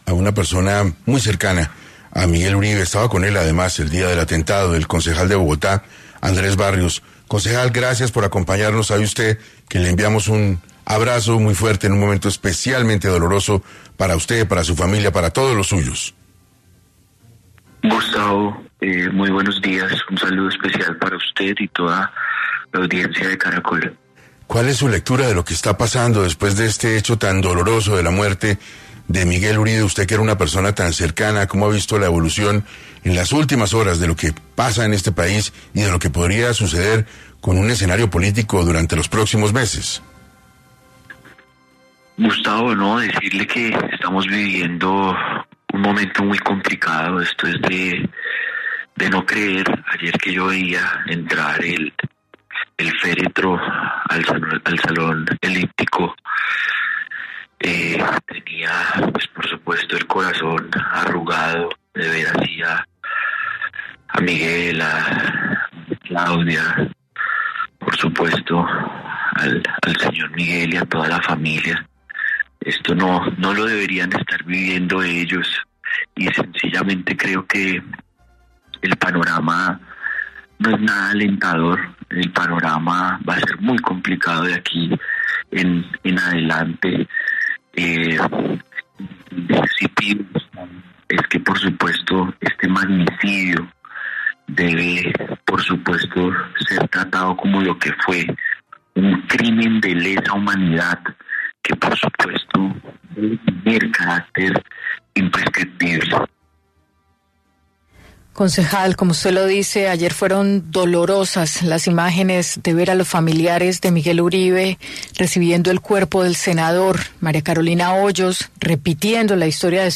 El concejal, Andrés Barrios quien se encontraba con Miguel Uribe el día del atentado, habló en 6AM, sobre cuál es el legado que deja Miguel para la política Colombia
El concejal, Andrés Barrios, quien se encontraba con Miguel Uribe el día del atentado, estuvo en el programa 6AM de Caracol Radio hablando cuál es el legado que deja Miguel para la política Colombia y cuáles son los cambios que traerá su fallecimiento.